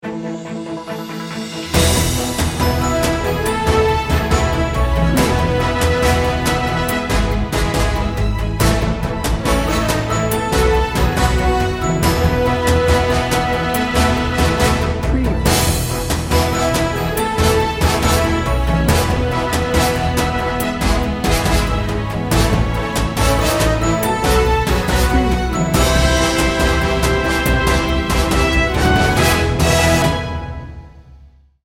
Powerful and heroic